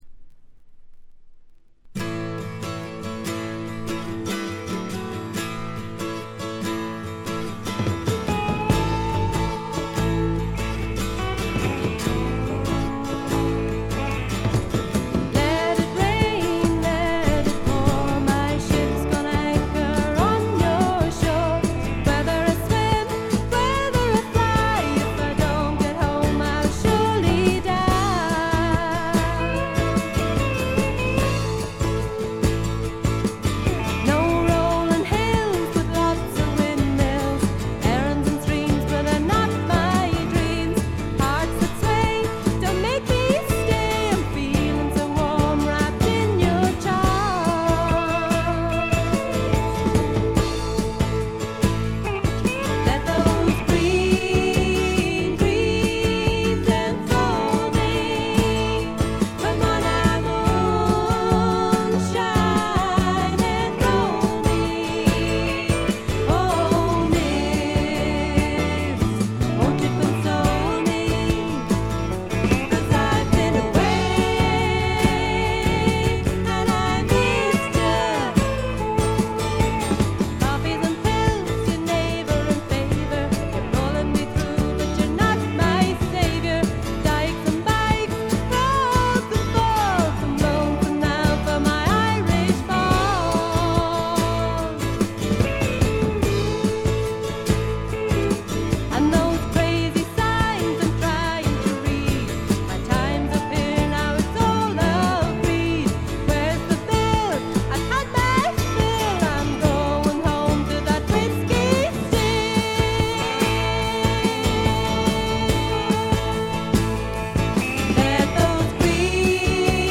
ホーム > レコード：英国 SSW / フォークロック
ほとんどノイズ感無し。
ひとことで言って上品で風格のあるフォーク･ロックです。
試聴曲は現品からの取り込み音源です。